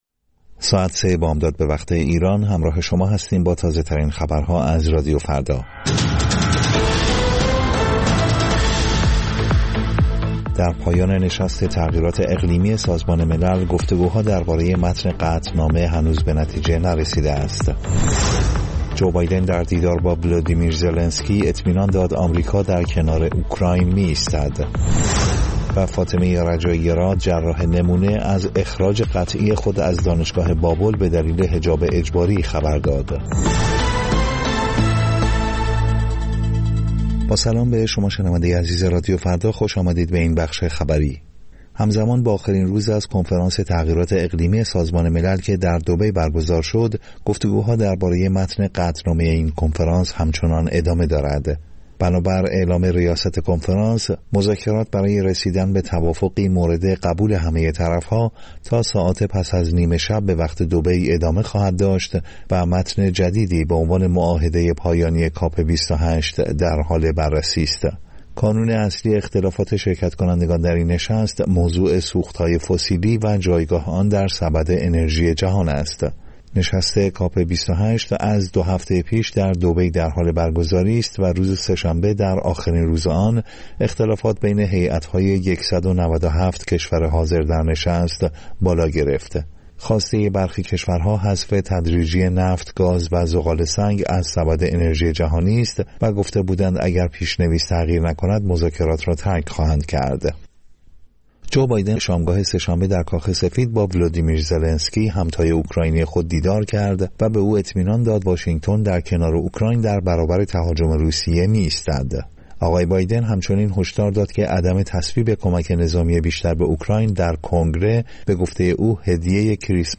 سرخط خبرها ۳:۰۰